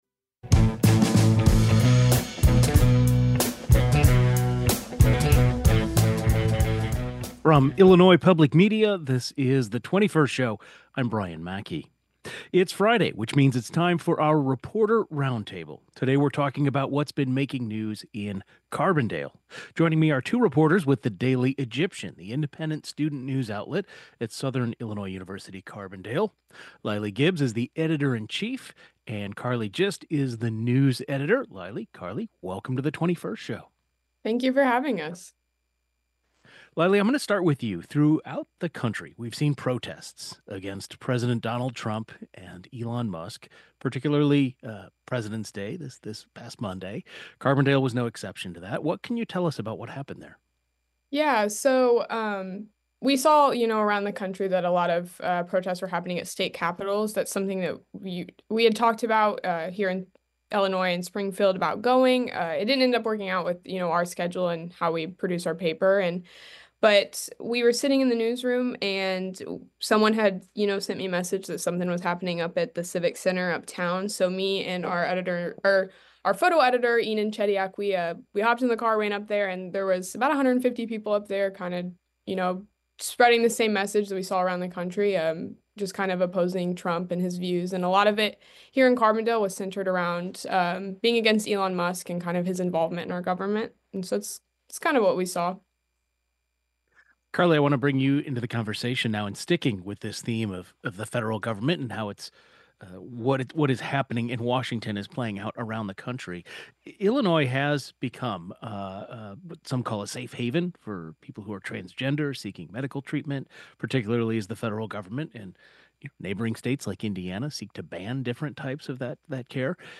It's Friday, which means it's time for our Reporter Roundtable. Today we'll be visiting Carbondale.